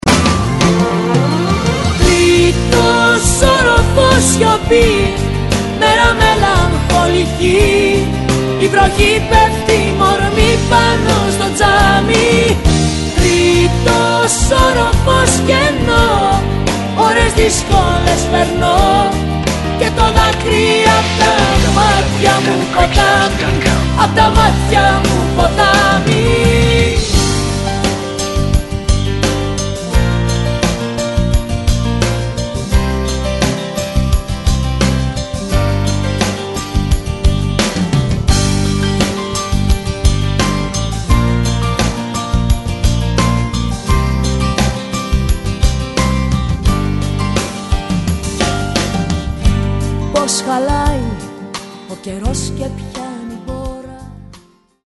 non-stop dance hits